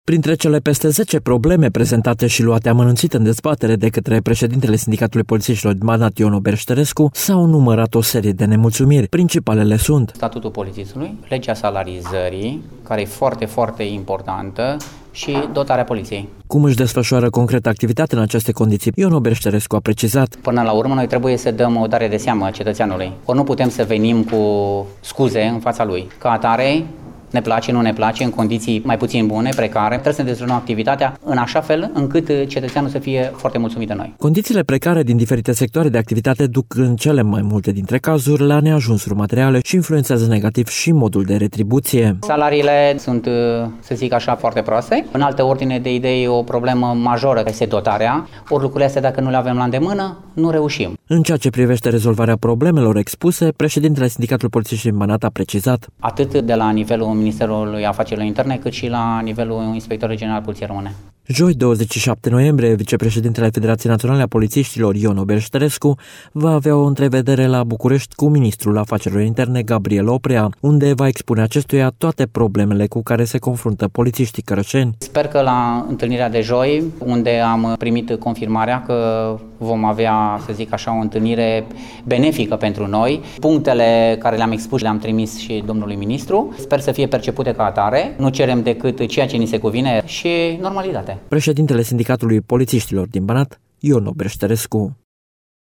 Membrii şi conducerea Federaţiei Naţionale a Poliţiştilor din România şi Sindicatul Poliţiştilor din Banat au prezentat, în cadrul unei întâlniri comune, problemele şi nemulţumirile cu care se confruntă zi de zi.